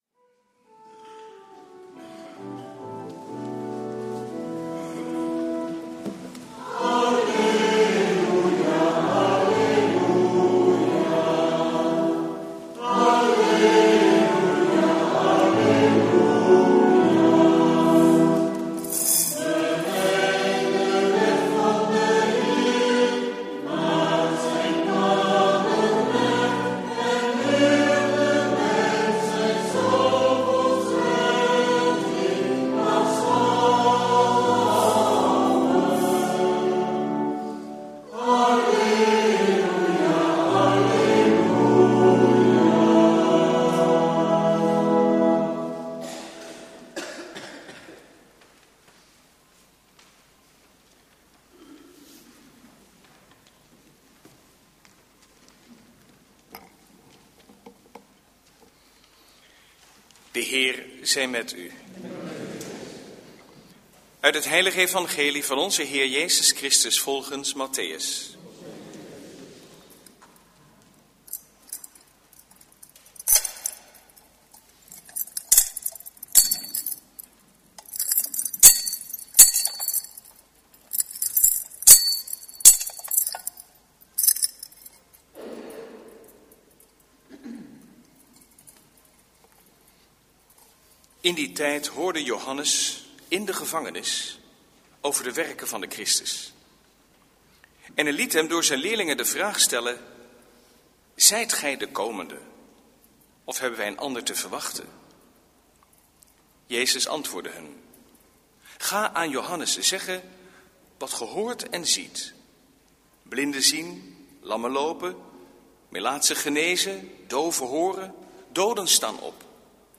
Eucharistieviering vanuit de H. Jozef te Wassenaar (MP3)